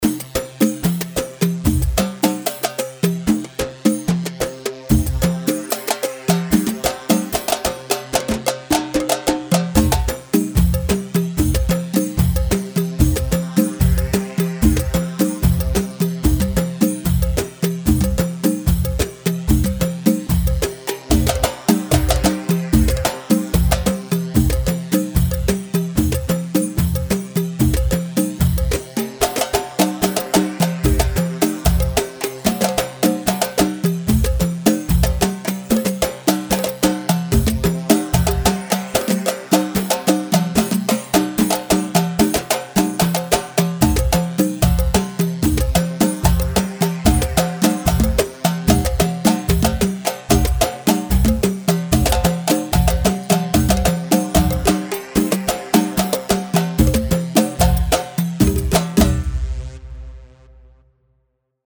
Khbeiti 4/4 74 خبيتي
Khbeiti-4-4.-74-mix.mp3